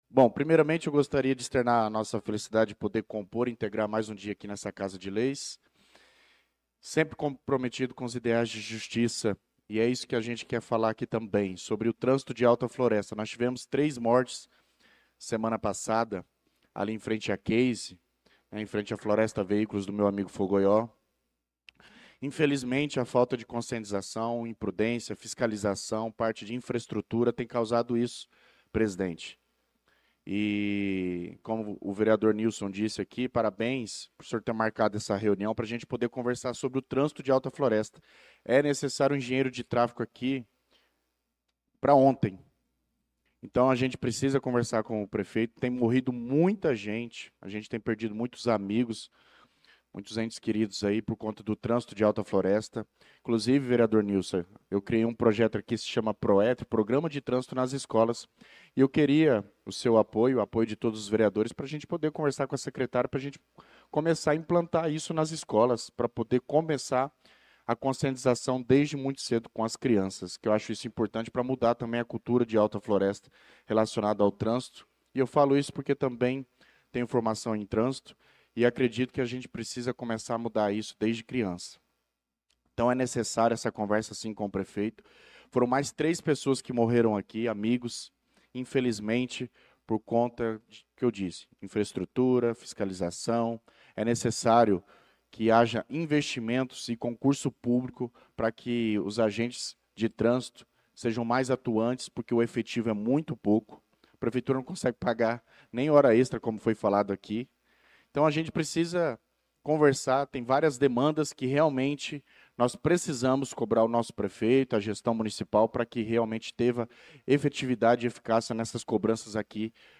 Pronunciamento do vereador Douglas Teixeira na Sessão Ordinária do dia 25/08/2025.